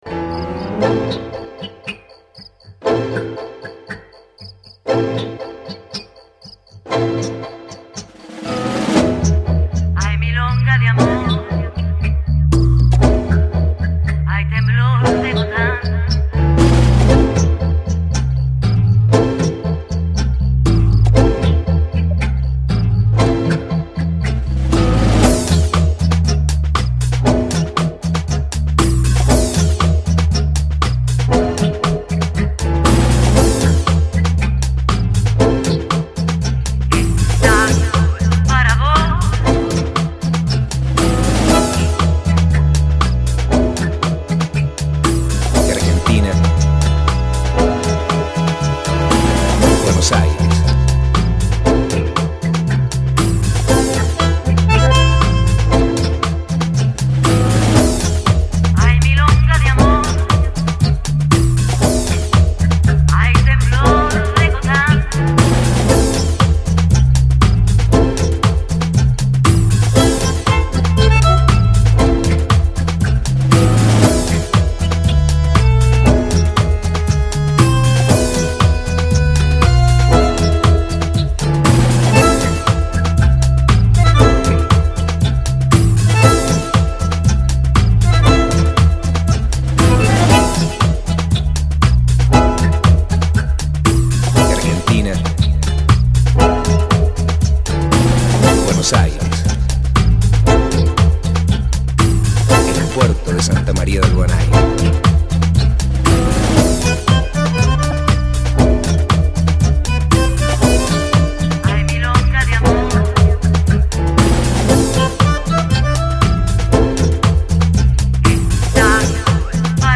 涉及分类：电子音乐 Electronica